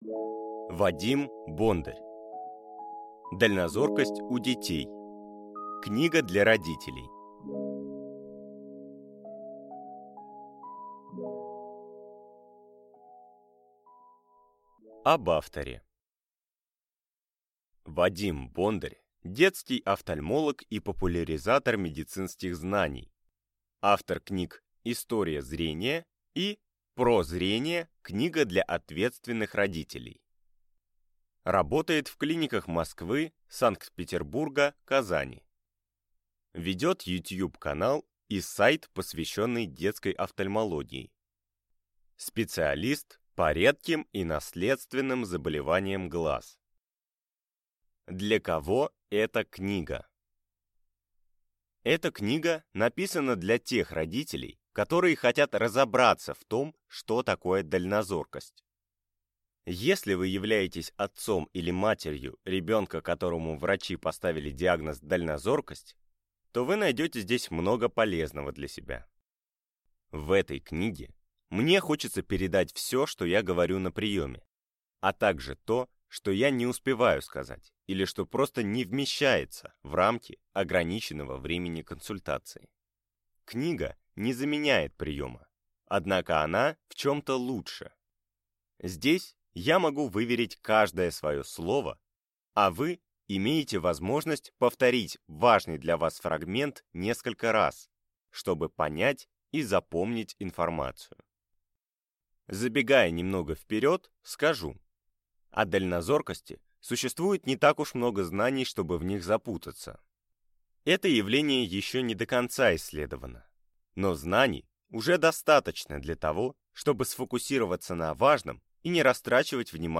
Аудиокнига Дальнозоркость у детей. Книга для родителей | Библиотека аудиокниг